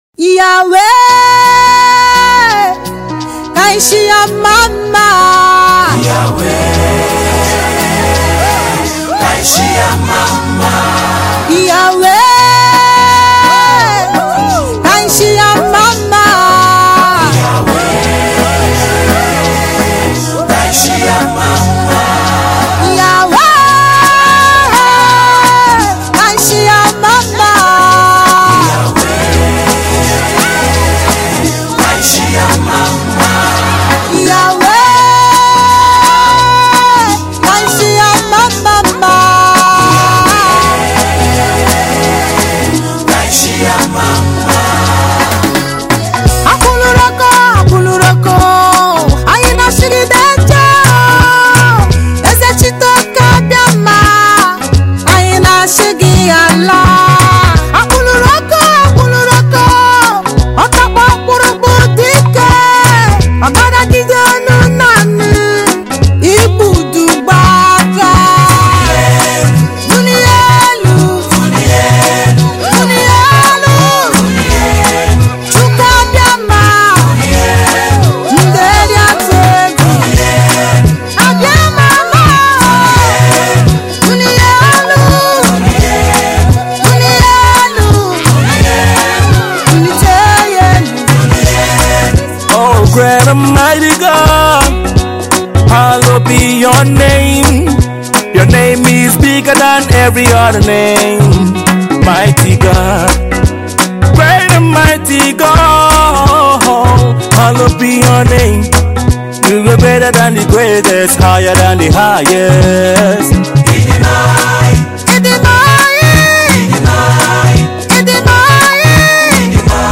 Afro-Fusion
dancehall